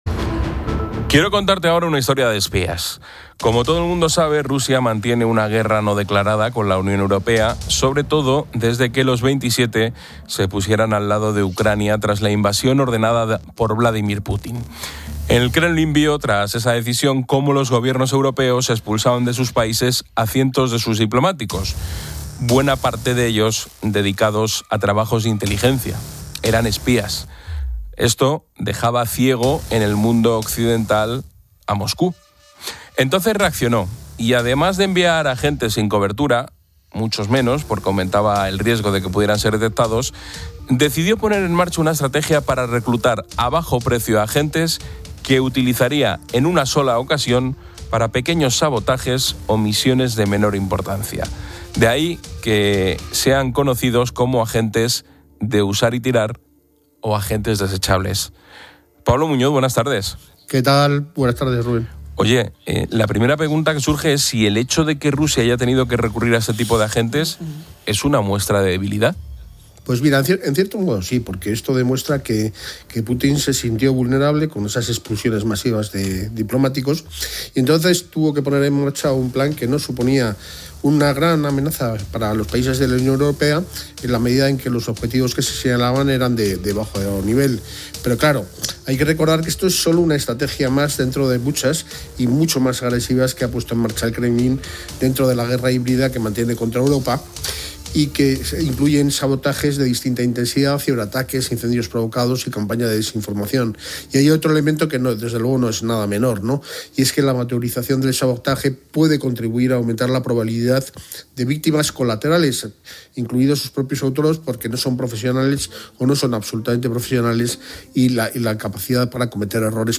experto en conflictos